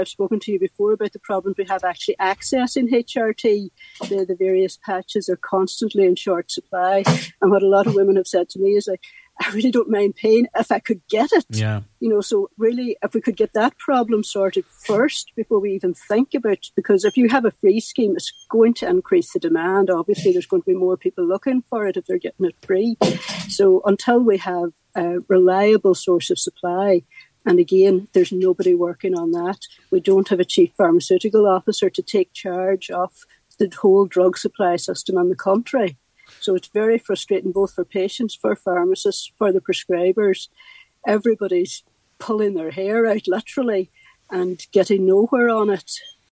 She told today’s Nine til Noon Show that supply issues need to be addressed also: